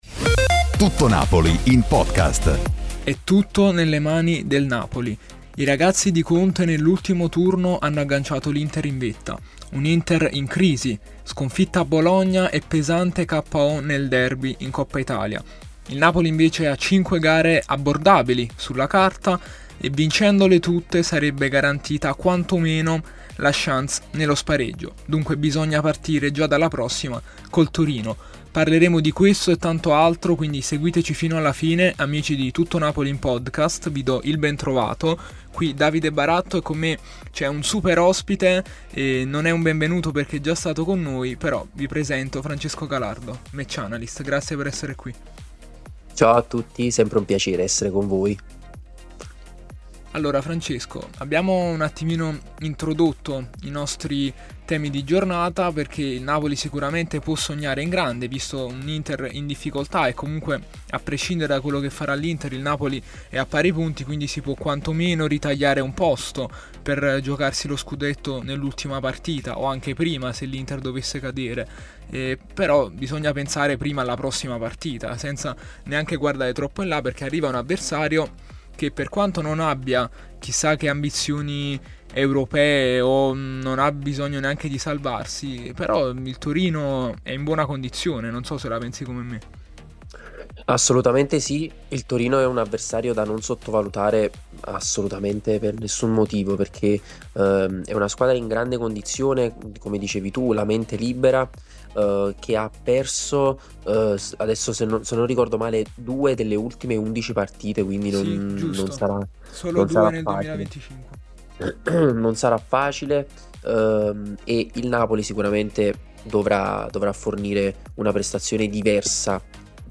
con ospite il match analyst